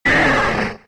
Cri de Caninos K.O. dans Pokémon X et Y.